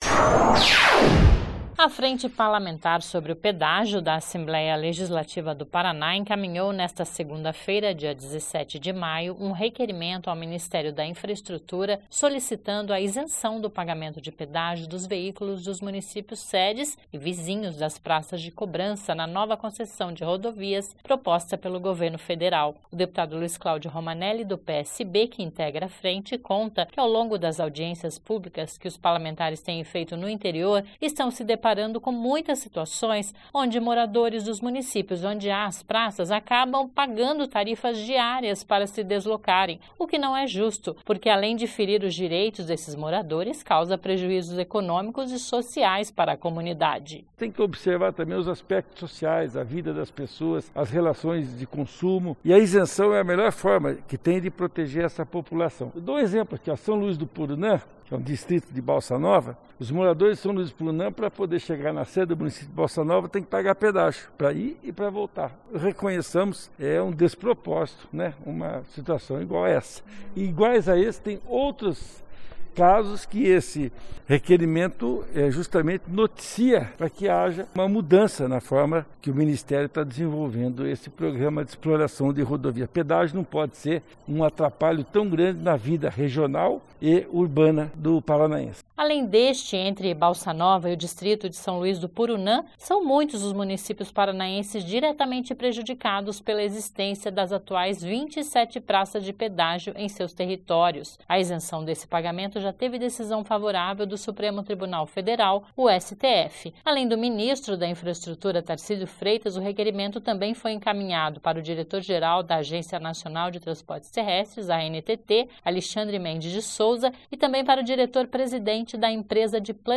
O deputado Luiz Claudio Romanelli (PSB), que integra a Frente, conta que, ao longo das audiências públicas que os parlamentares têm feito no interior, estão se deparando com muitas situações onde moradores dos municípios onde há praças, acabam pagando tarifas diárias para se deslocarem, o que não é justo, porque, além de ferir os direitos dos moradores, causa prejuízos econômicos e sociais para a comunidade.
(Sonora)